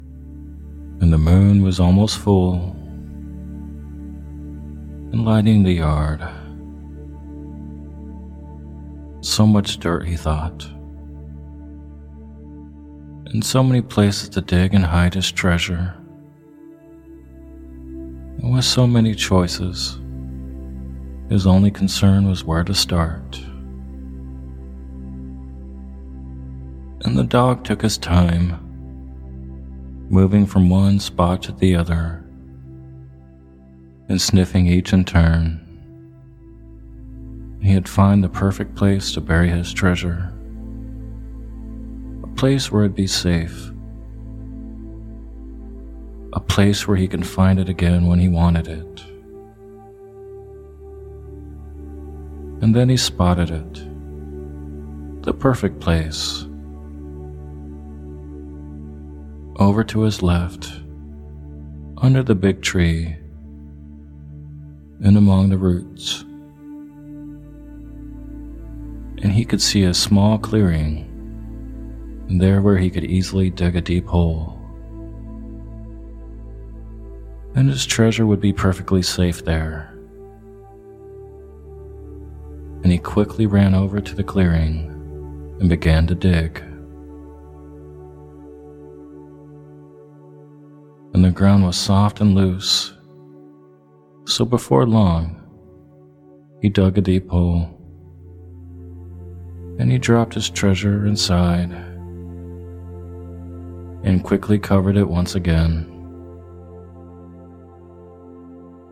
Story Based Meditation “Finding Lost Treasure”
Lost-Treasure-Gratitude-Sample-Daytime-Story-Based.mp3